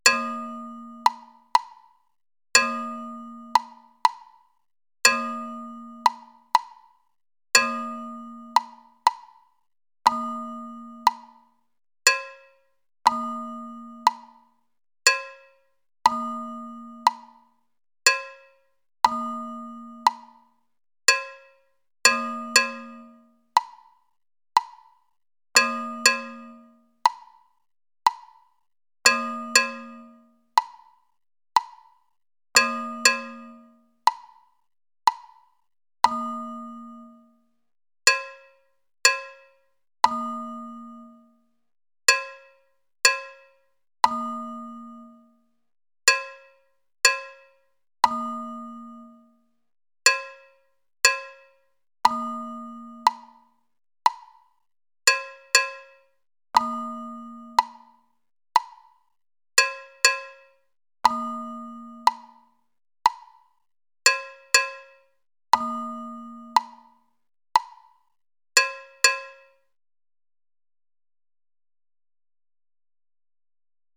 MIDI audio of the 5 cycles at 60 BPM